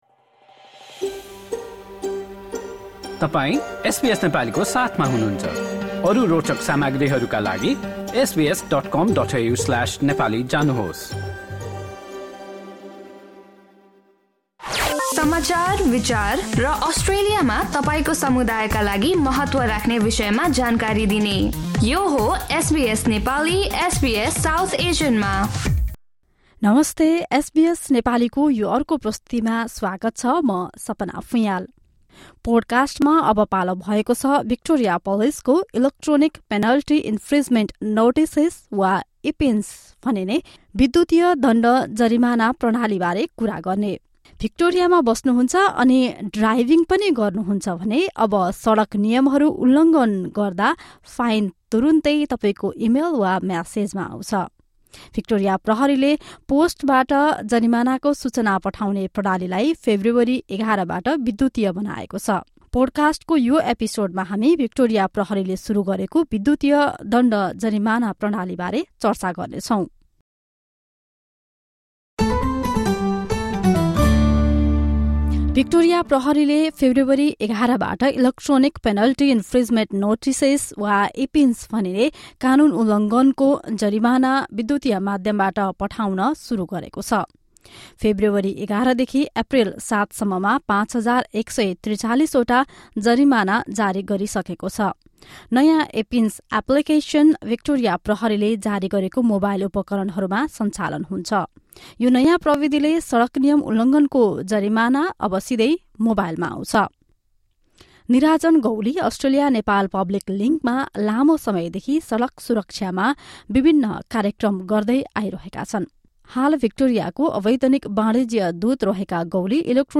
भिक्टोरिया प्रहरीले पोस्टबाट जरिमानाको सूचना पठाउने प्रणालीलाई फेब्रुअरी ११ बाट विद्युतीय बनाएको छ। ‘इलेक्ट्रोनिक पेनल्टी इन्फ्रिज्मेन्ट नोटिसेस् वा (इपिन्स) भनिने विद्युतीय दण्ड जरिमाना प्रणालीबारे एक रिपोर्ट सुन्नुहोस्।